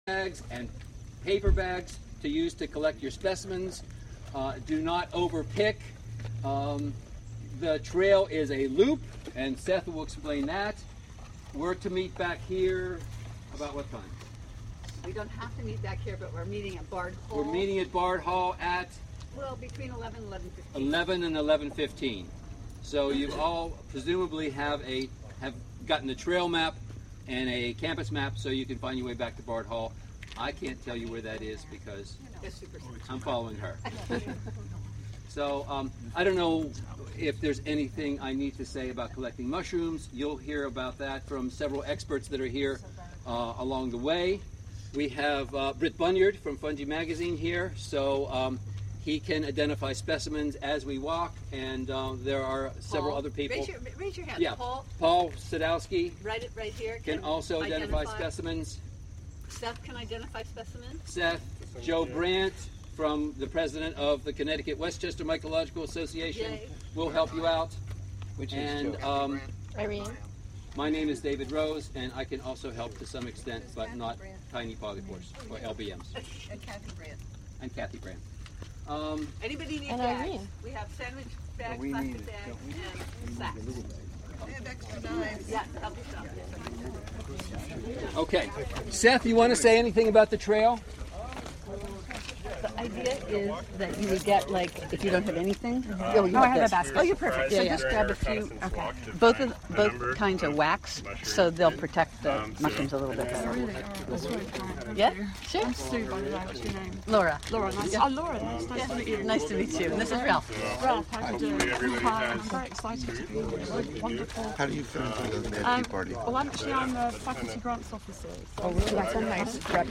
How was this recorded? Mushroom Walk at John Cage Mycology Day (Audio) Live from The John Cage Trust Recorded and streamed by Wave Farm.